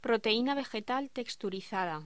Locución: Proteína vegetal texturizada
voz
Sonidos: Voz humana